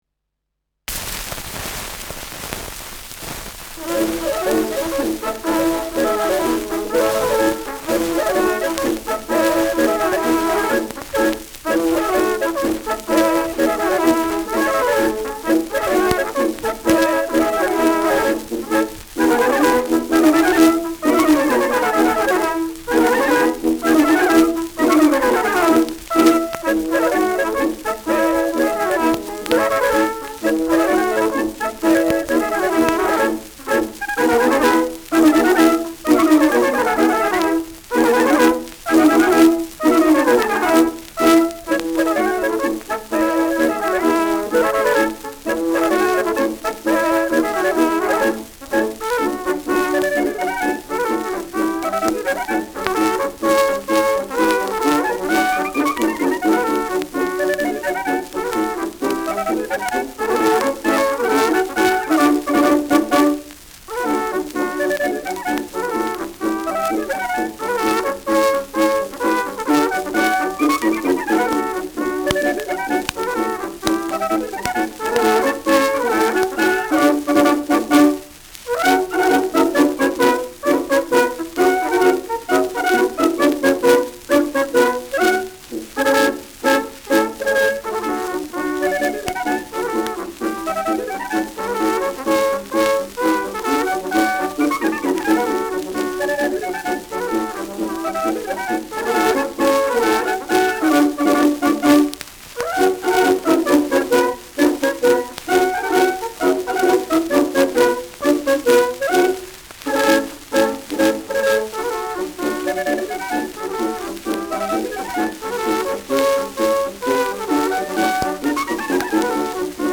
Schellackplatte
abgespielt : leichtes Leiern : präsentes Rauschen : gelegentliches Knacken : stärkeres Knacken bei 1’12“ : leichteres Knacken bei 2’00’’
Kapelle Pokorny, Bischofshofen (Interpretation)